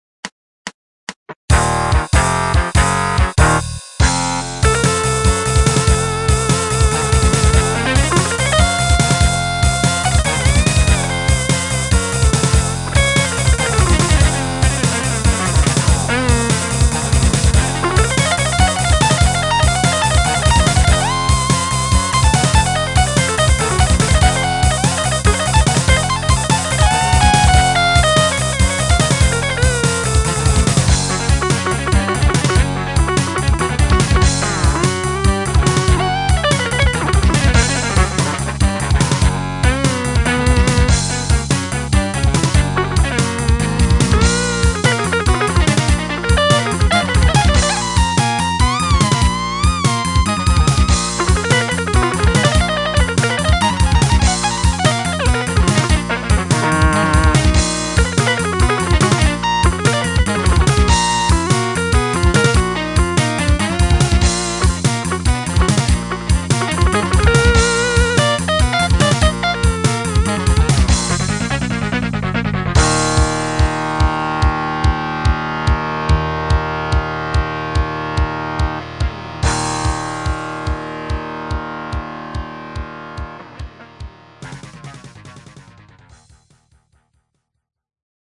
Metal 80's
MIDI